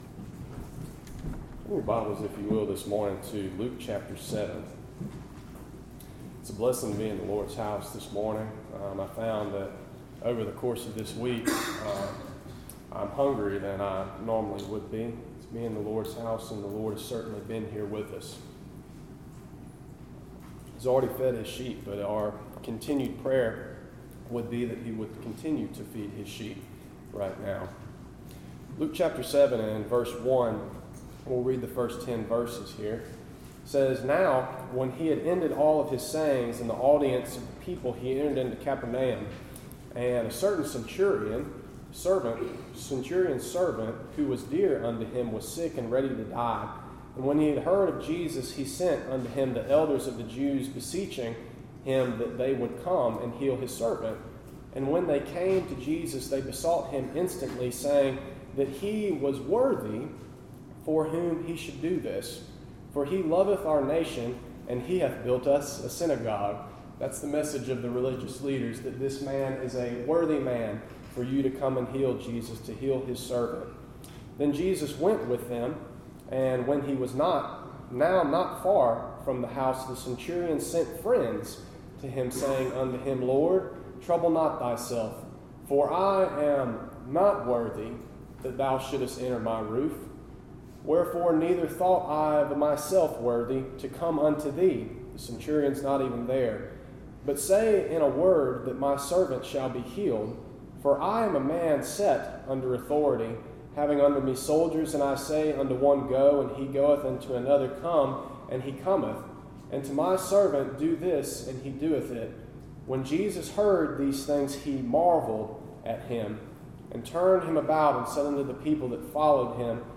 Series: Gifts in the Church Topic: Sermons